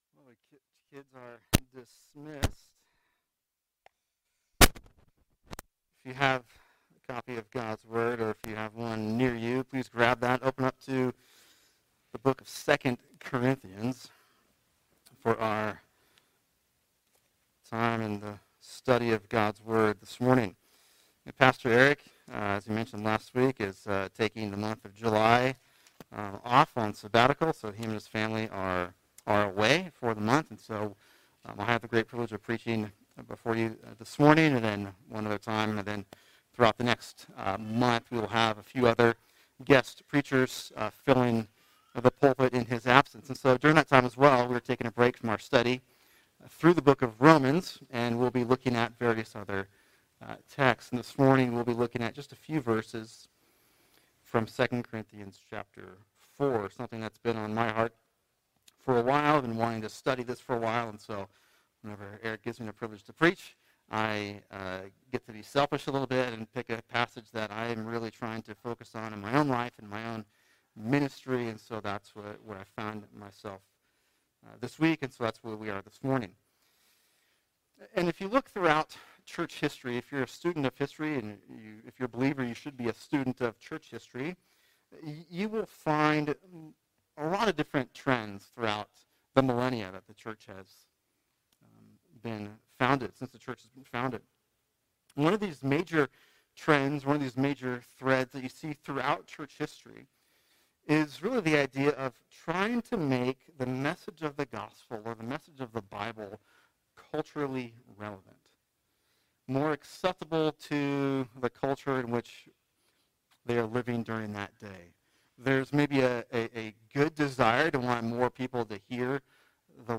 [sermon] 2 Corinthians 4:1-6 Scripture’s Sufficiency For Ministry | Cornerstone Church - Jackson Hole
(Apologies for the poor audio quality of this recording)